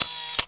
flag_drop.wav